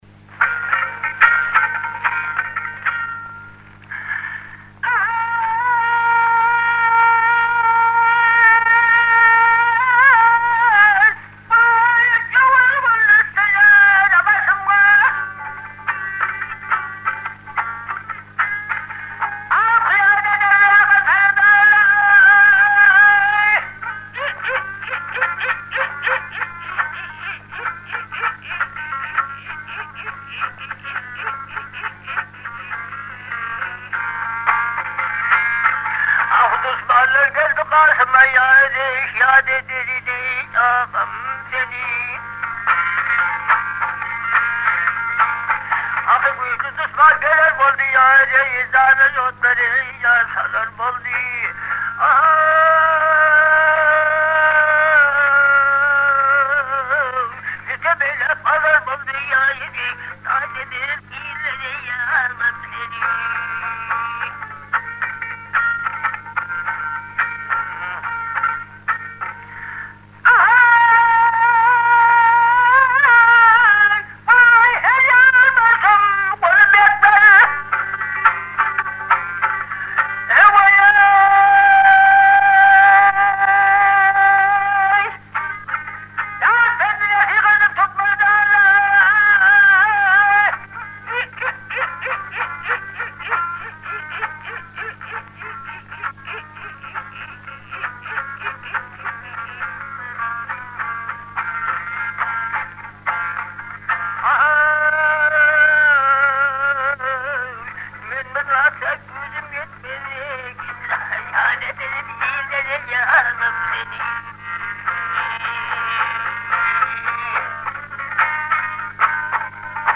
Türkmen halk aýdym-sazlary